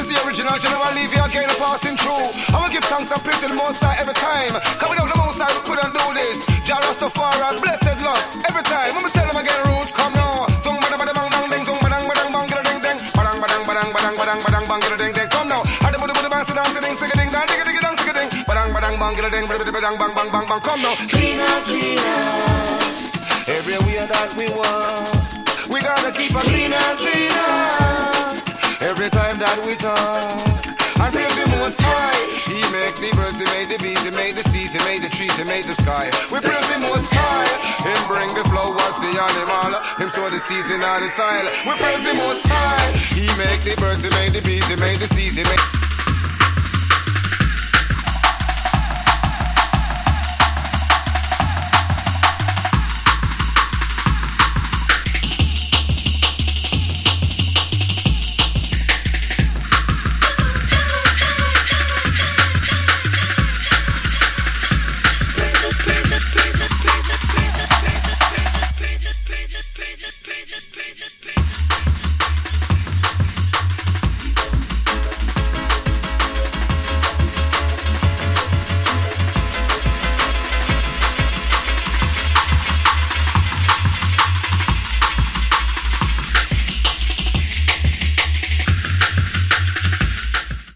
Chopstick Dubplate Version
Dub - [Jungle Dub Mix]